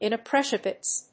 音節in・ap・pre・cia・tive 発音記号・読み方
/ìnəpríːʃəṭɪv(米国英語)/